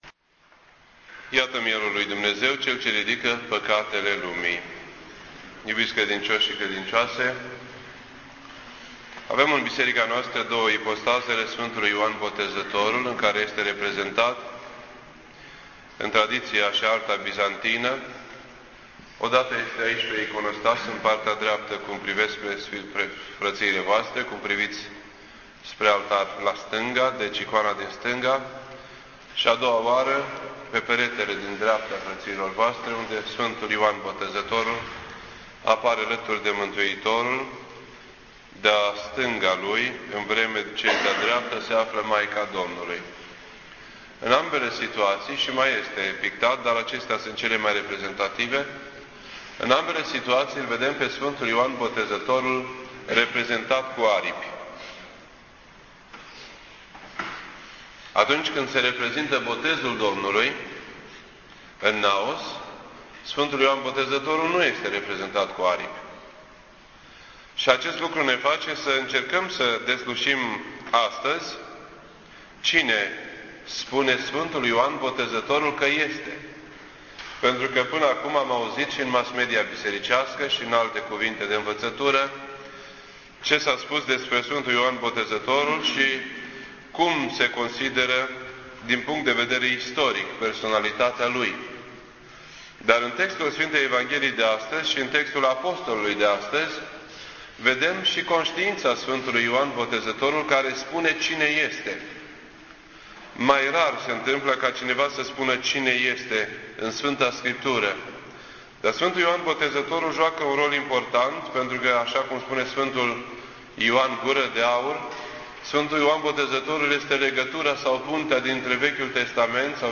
This entry was posted on Friday, January 9th, 2009 at 3:59 PM and is filed under Predici ortodoxe in format audio.